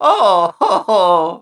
Super Mario Oohhohoh Sound Effect Free Download